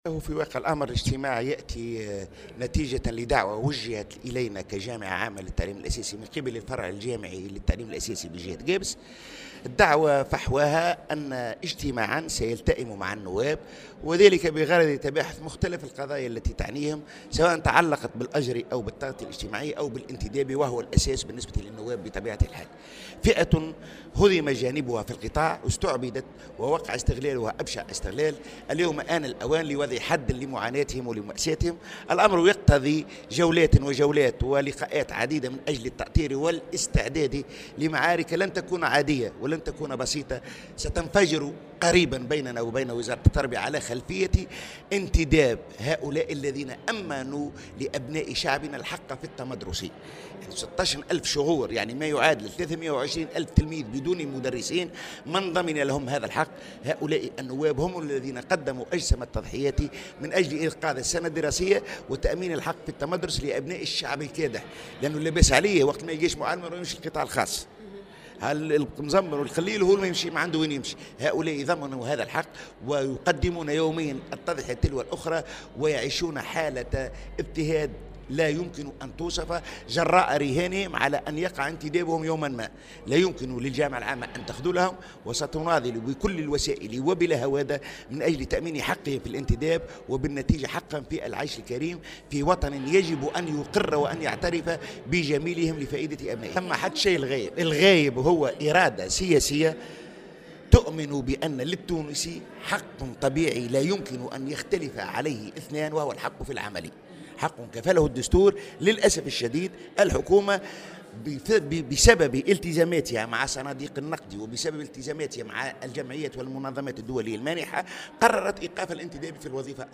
تصريح
خلال إجتماع عقد اليوم الأحد في ولاية قابس